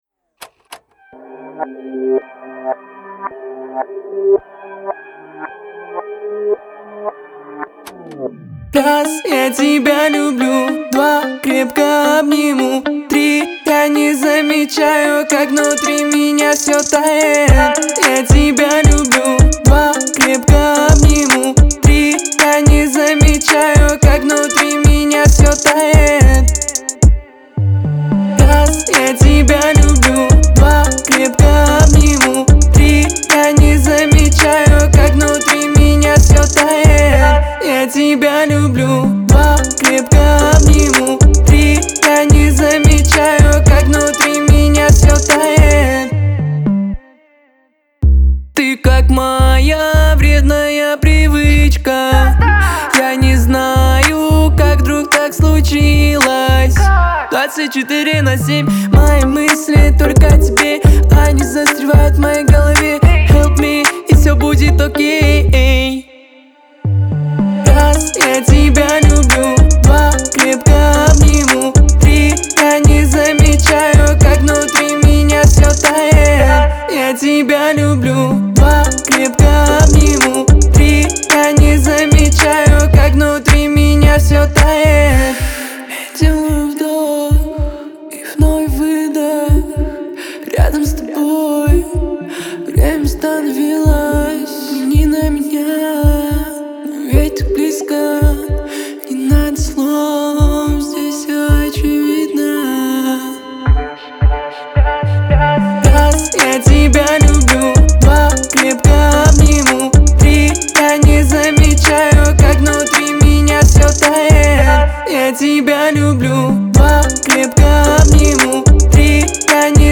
это яркая и эмоциональная песня в жанре поп